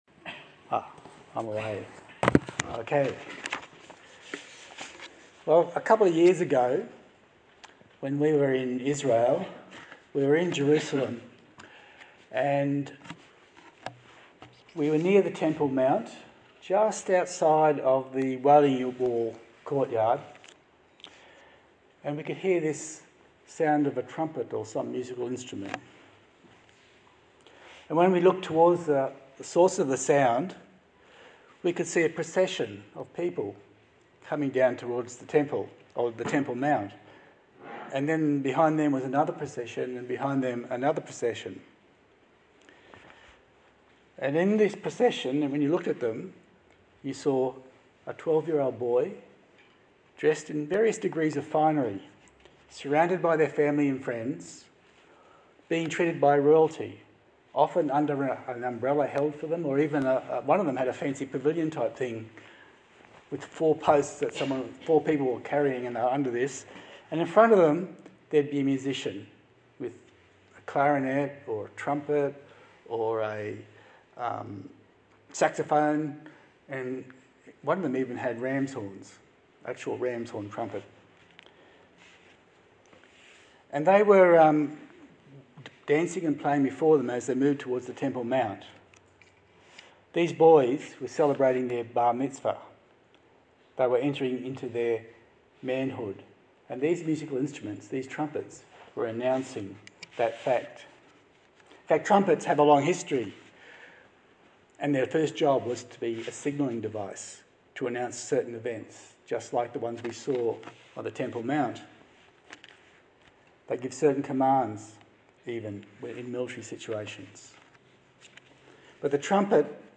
A sermon on the book of Matthew